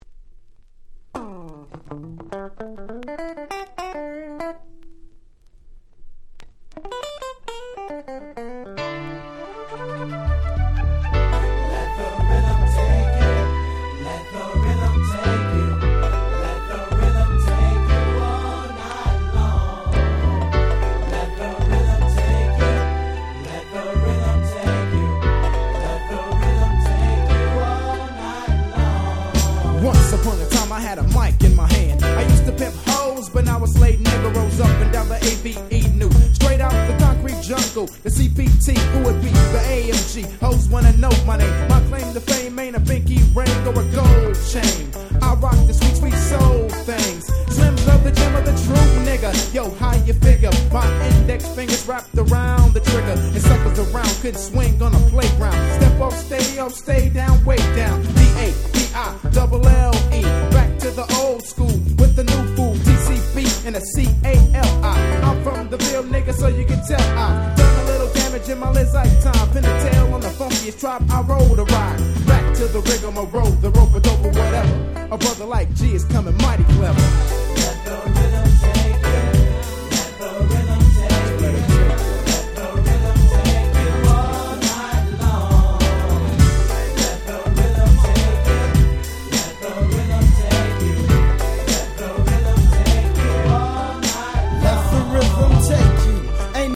92' Smash Hit West Coast Hip Hop !!
キャッチーで軽快な